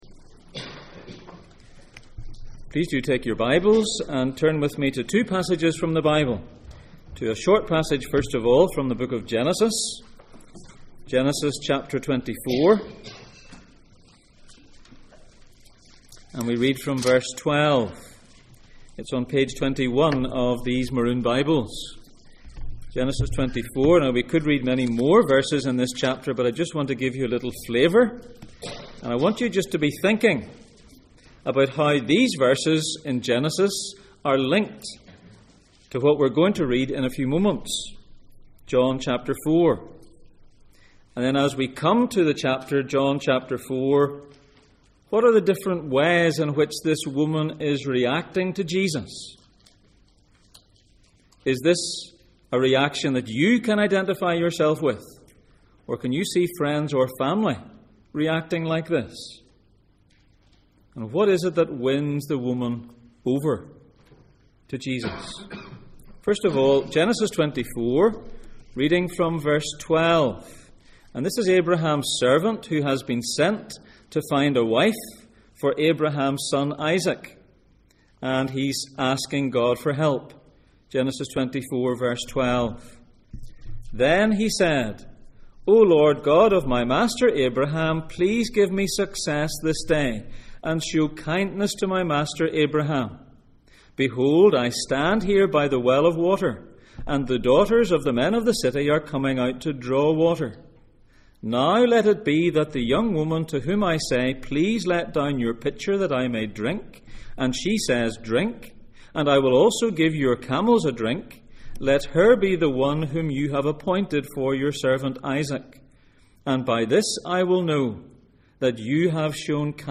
In praise of love Passage: Genesis 24:12-15, Genesis 24:57-58, John 4:1-30, Jeremiah 2:13 Service Type: Sunday Morning